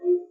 mobs_bunny_azure.ogg